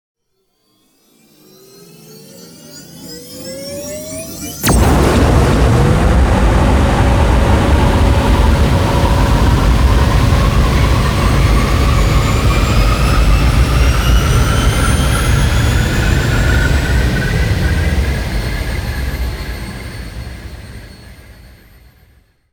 BeaconLaunch.wav